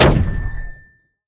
poly_explosion_flashbang.wav